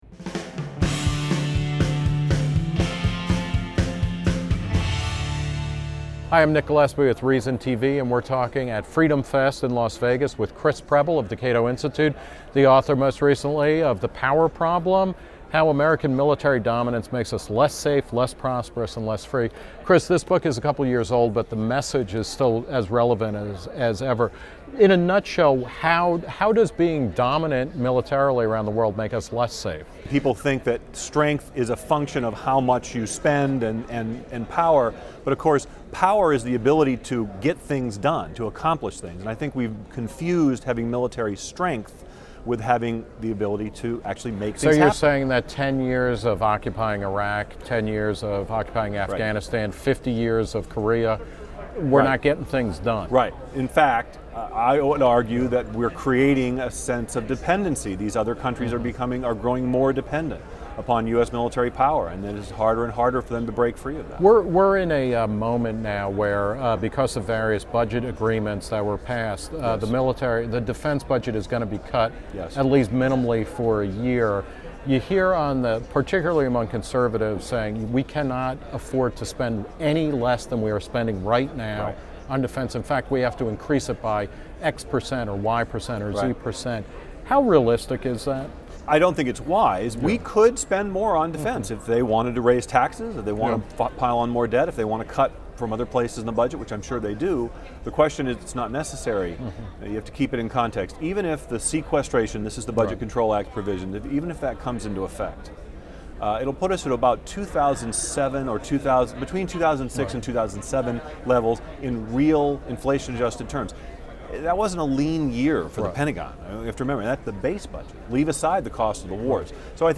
ReasonTV spoke with over two dozen speakers and attendees and will be releasing interviews over the coming weeks.